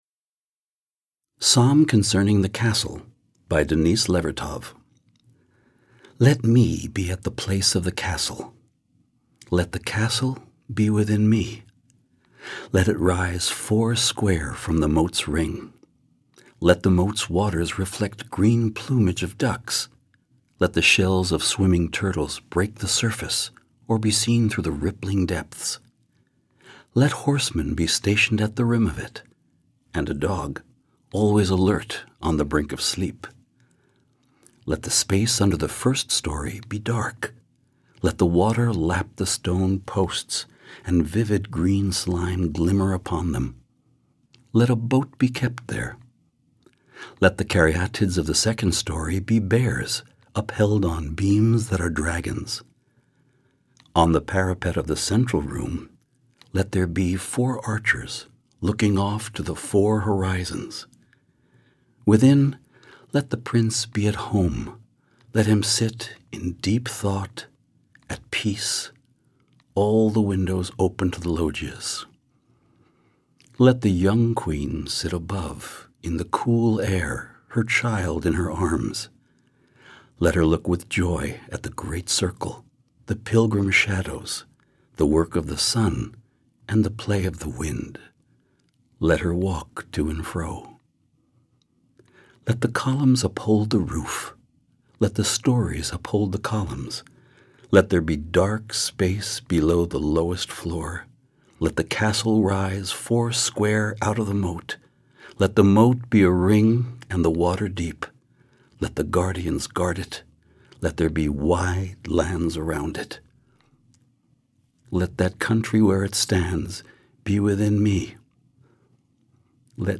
Peter Friedman reads "Psalm Concerning the Castle" by Denise Levertov
In celebration of National Poetry Month, every day we're posting a new poem from the spoken-word album Poetic License, a three-CD set that features one hundred performers of stage and screen reading one hundred poems selected by the actors themselves.
Peter Friedman has appeared in film, television, and on Broadway, most recently in Twelve Angry Men.